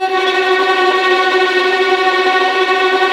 Index of /90_sSampleCDs/Roland LCDP13 String Sections/STR_Violins Trem/STR_Vls Trem wh%